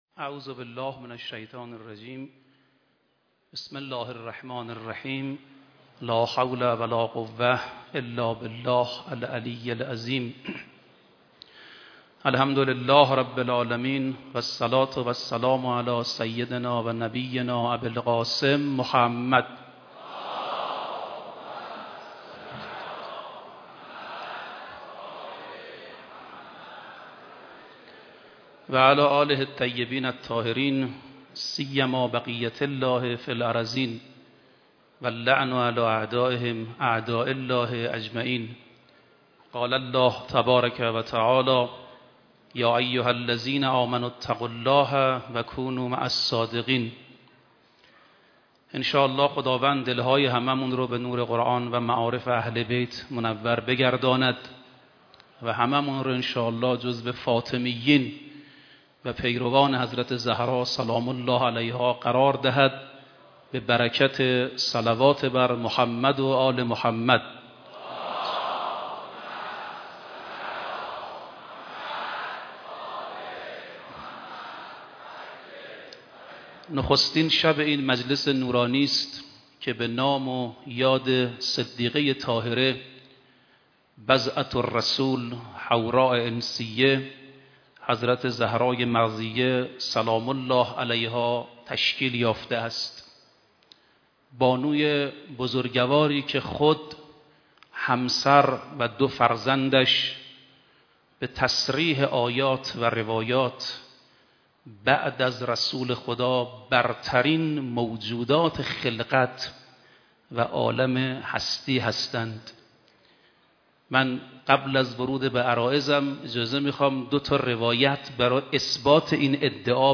در حسینیه امام خمینی(ره)
سخنرانی
مداحی